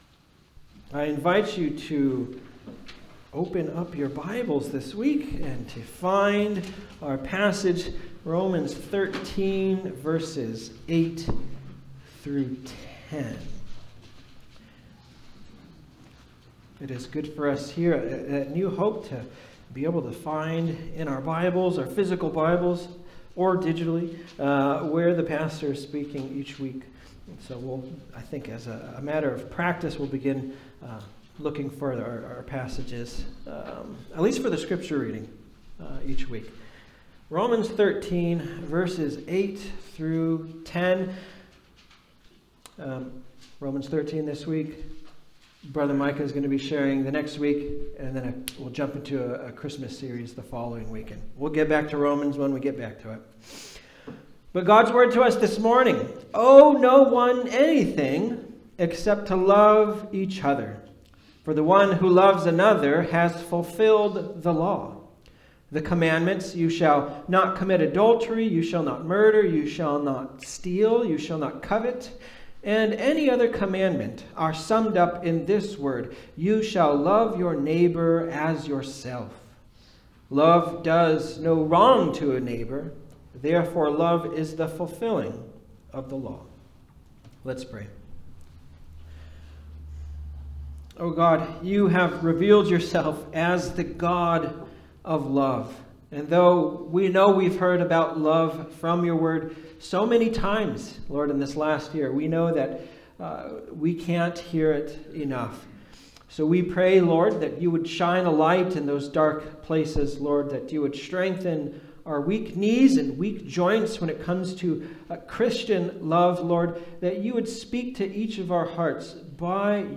Passage: Romans 13:8-10 Service Type: Sunday Service